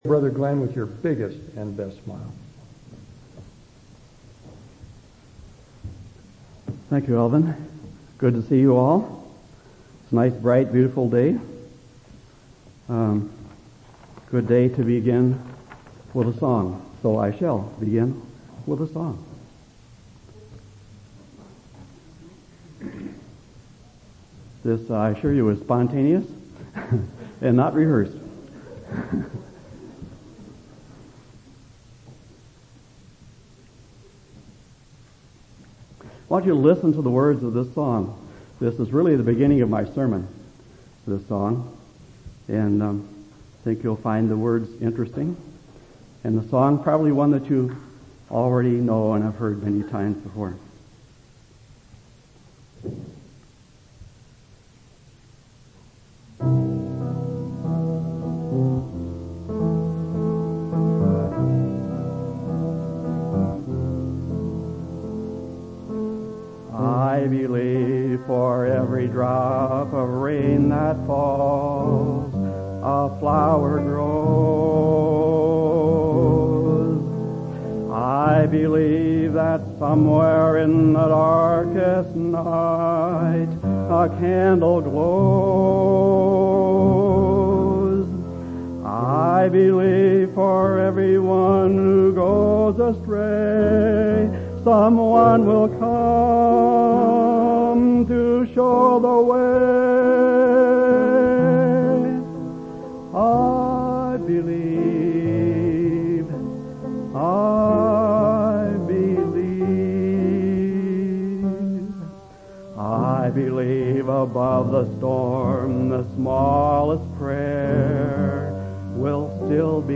10/12/2003 Location: Temple Lot Local Event